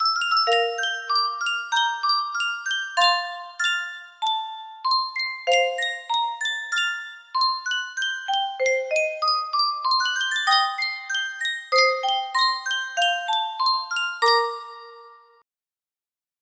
Yunsheng Music Box - IGSRDAWMSAL 6573 music box melody
Full range 60